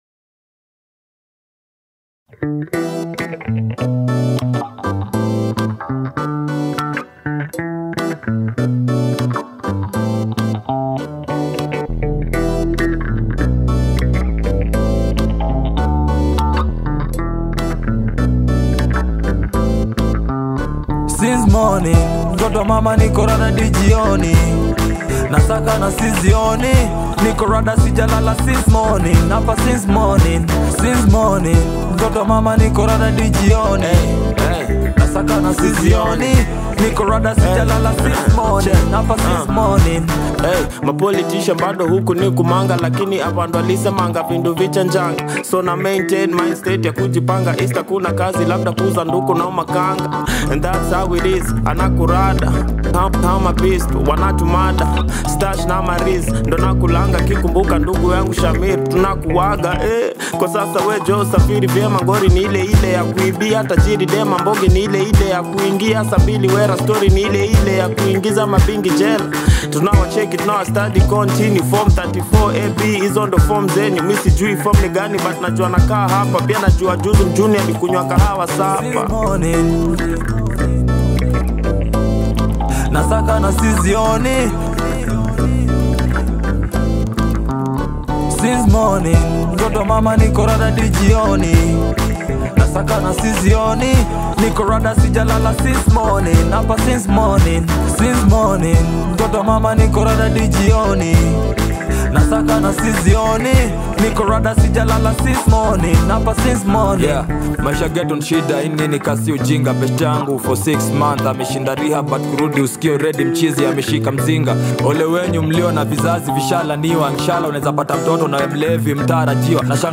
hip hop group from Kenya